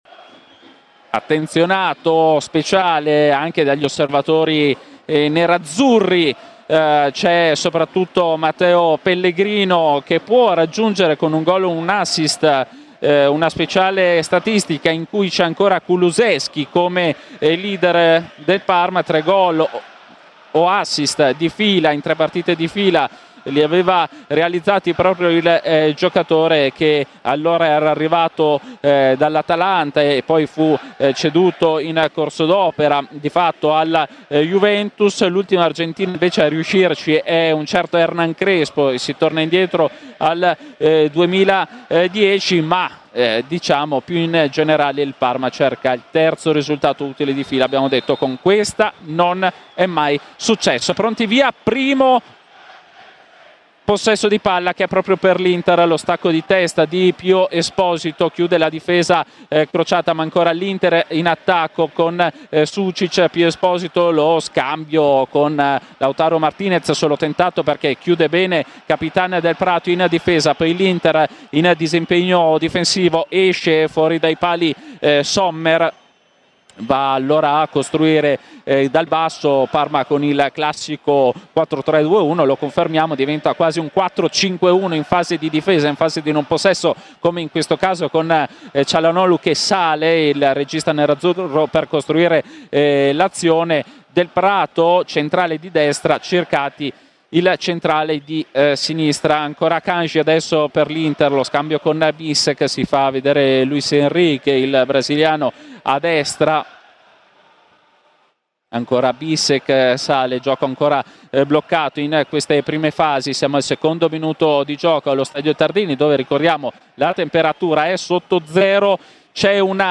Con la radiocronaca